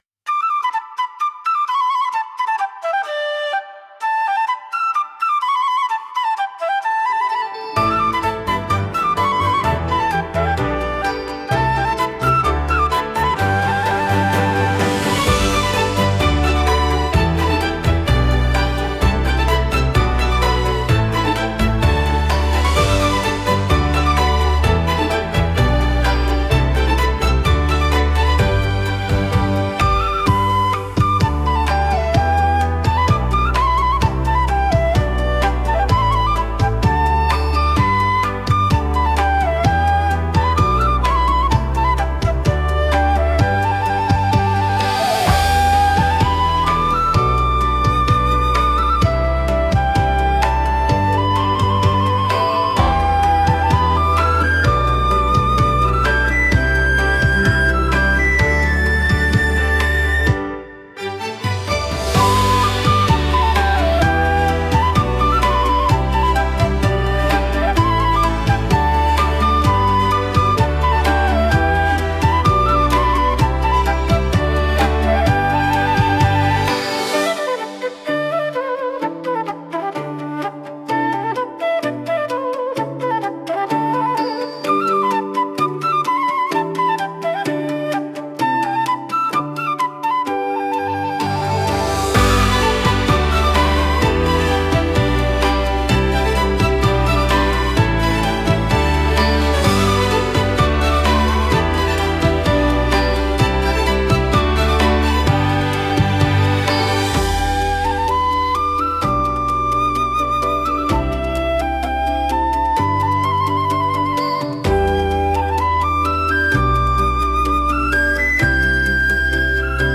沖縄の自然と文化にインスパイアされた、リズミカルで温かいサウンド。
太鼓や民族的なリズム、明るい旋律が、人々の力強さと陽気なエネルギーを表現します。
島の風景や祭りのような臨場感あふれる一曲です。